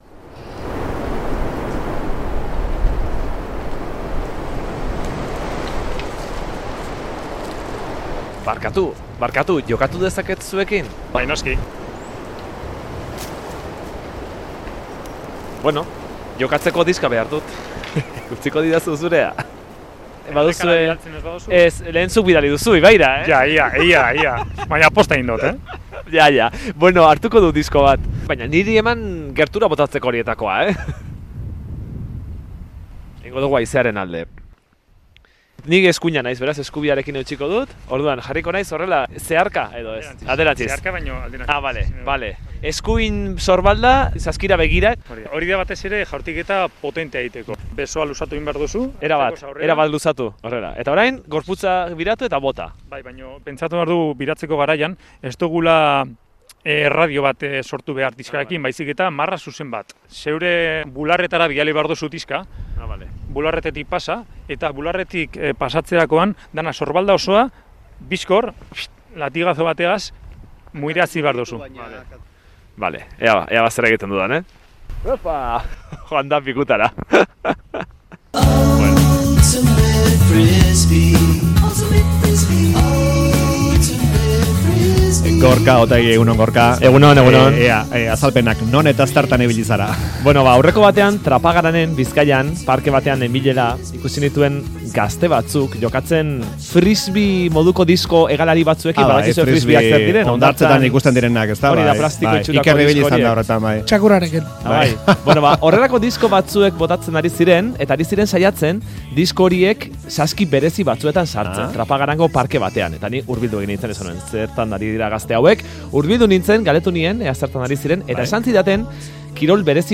Audioa: Plastikozko diskoekin jokatzen den kirolaren xehetasunak kontatu dizkigute Bilbao Disc Golf klubeko kideek Trapagaranen duten zelaian